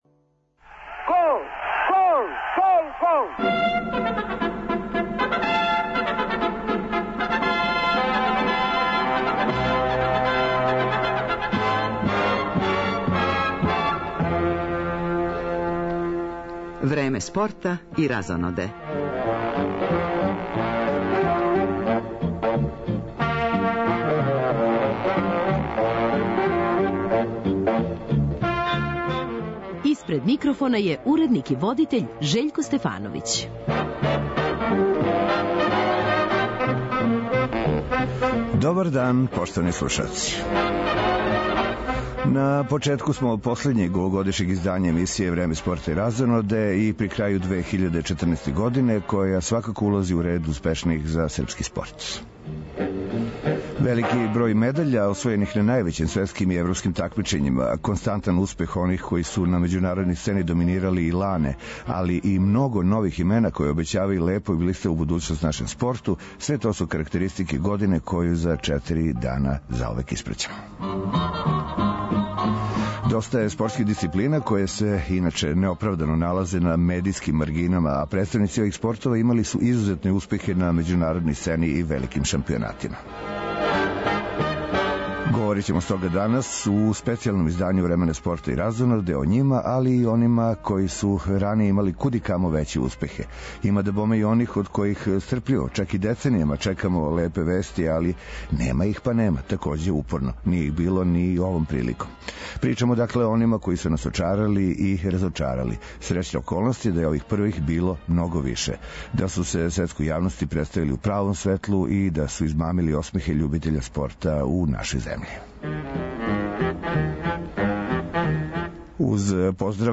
Последња овогодишња емисија посвећена је резултатима наших спортиста на великим такмичењима, на међународној и домаћој сцени. Разговарамо са најуспешнијима на крају године која је била плодоносна за српски спорт, говоримо о онима који су нас очарали и разочарали у години коју управо испраћамо.